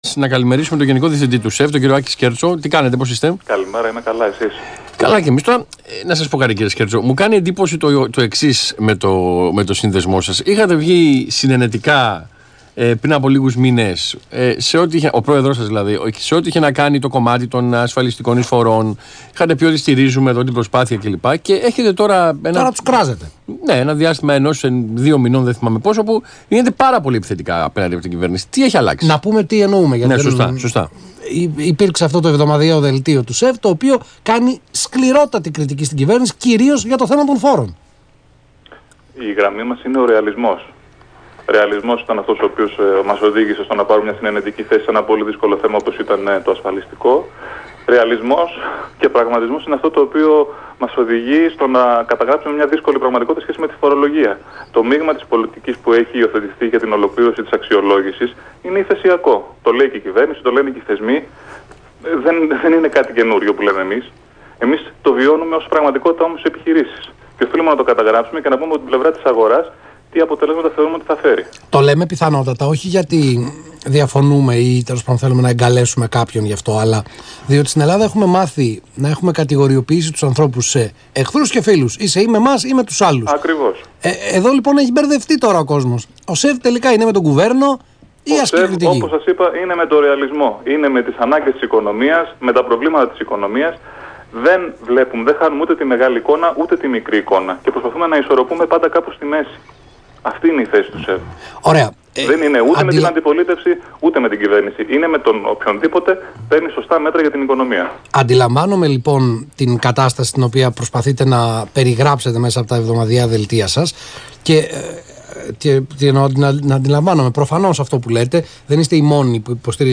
Συνέντευξη του Γενικού Διευθυντή του ΣΕΒ, κ. Άκη Σκέρτσου στον Ρ/Σ Αθήνα 9.84, 10/6/16